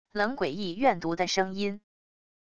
冷诡异怨毒的声音wav音频